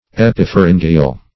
Search Result for " epipharyngeal" : The Collaborative International Dictionary of English v.0.48: Epipharyngeal \Ep`i*phar`yn*ge"al\, a. [Pref. epi- + pharyngeal.]
epipharyngeal.mp3